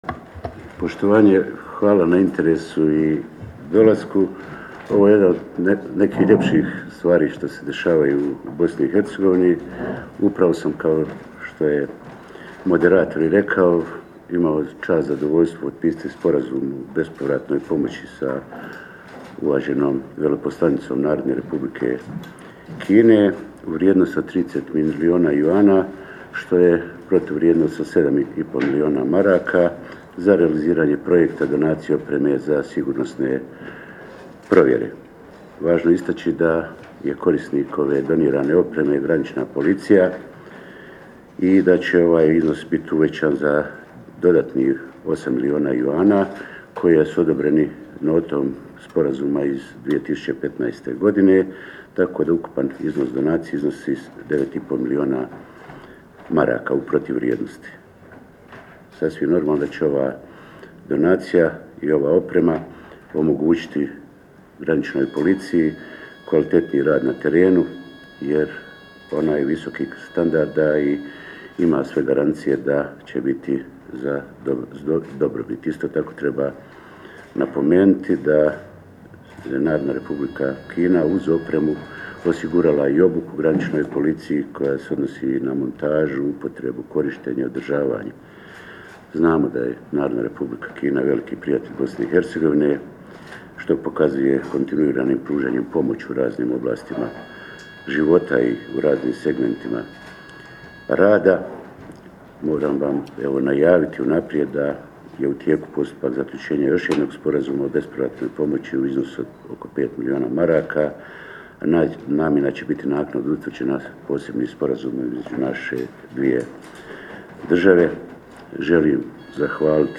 Након церемоније потписивања Споразума, којој су присуствовали и представници Граничне полиције БиХ, медијима су се обратили министар Беванда и амбасадорка Бо. АУДИО ЗАПИС изјава за медије < Претходна Следећа >